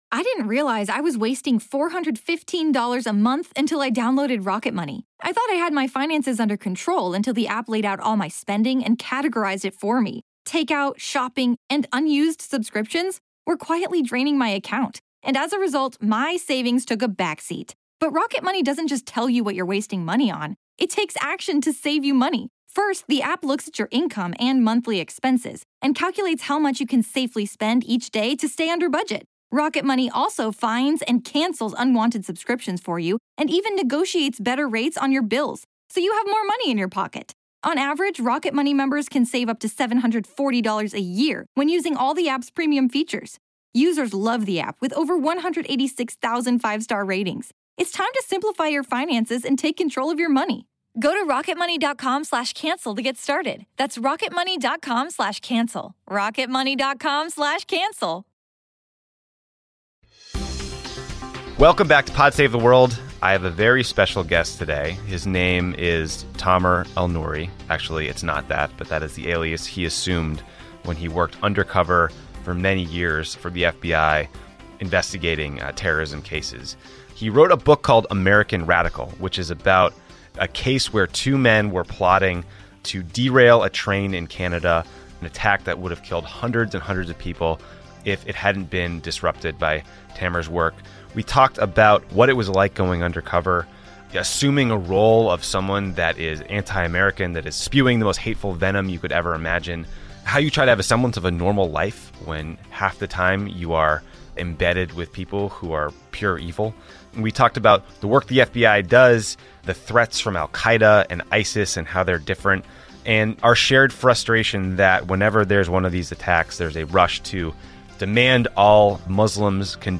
Tommy interviewed undercover FBI agent